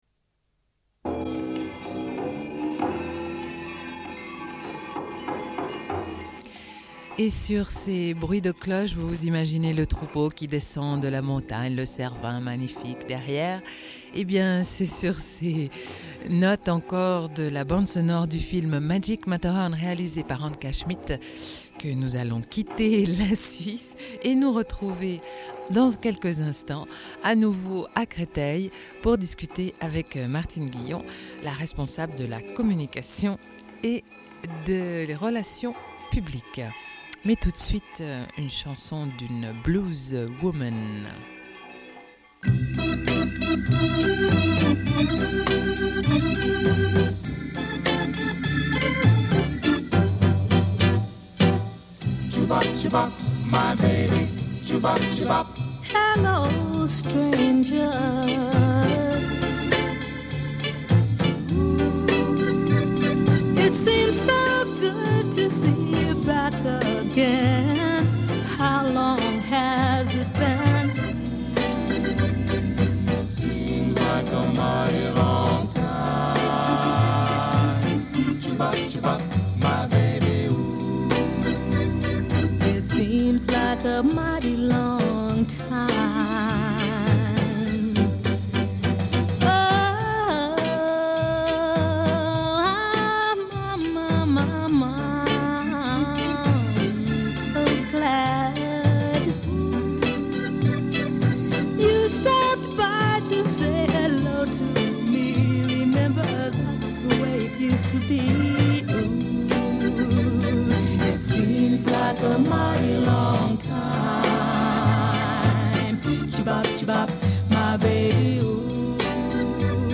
entretien
Une cassette audio, face B
Radio